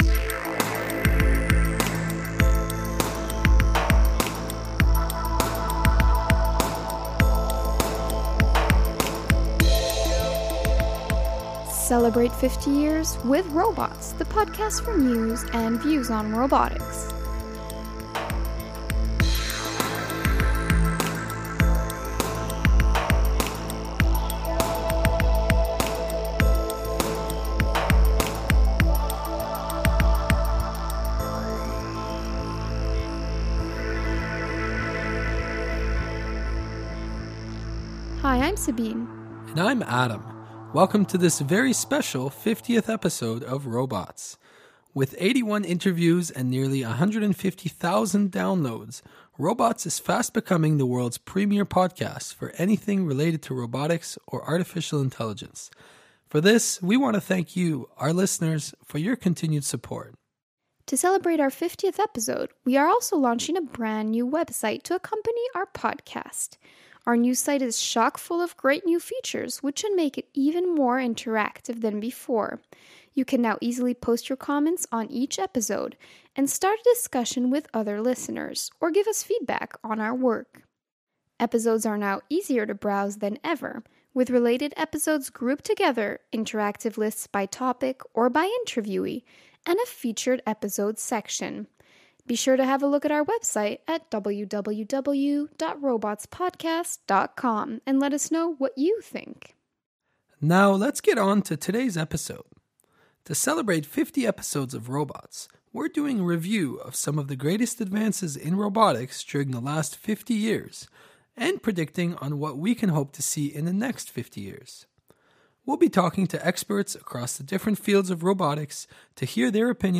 For the occasion we speak with 12 scientists about the most remarkable developments in robotics over the last 50 years and their prediction for the next half-century.